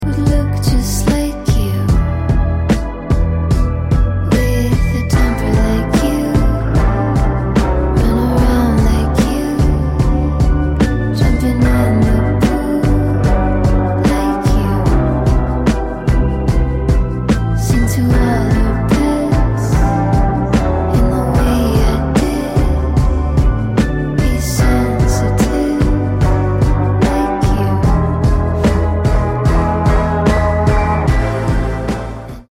Asmr Cozy Night Routine W/ Sound Effects Free Download